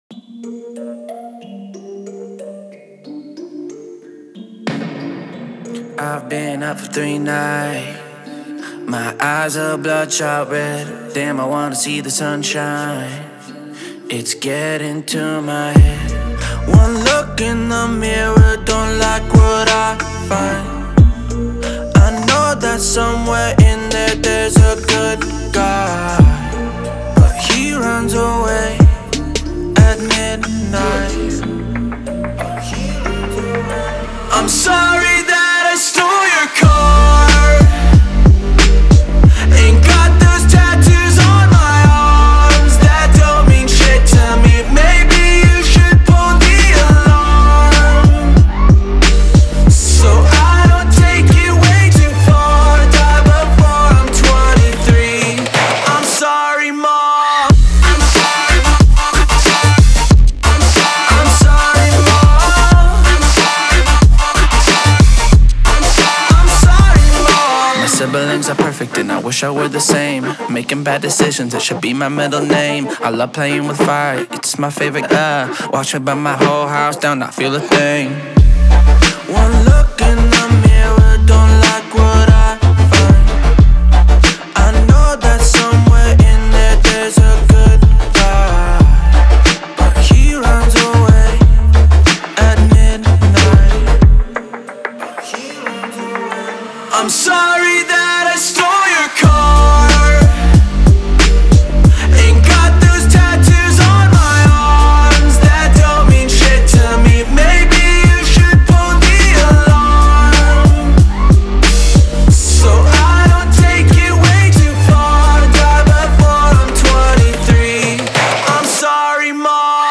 Trap, Epic, Happy, Euphoric, Energetic